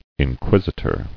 [in·quis·i·tor]